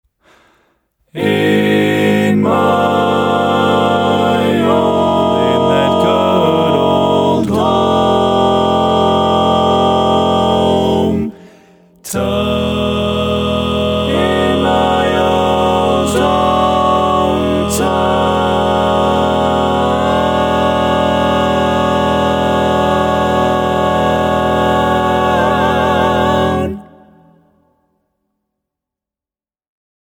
Key written in: E Major
How many parts: 6
Type: Barbershop
Comments: Lots of nasty chords - watch out!
All Parts mix: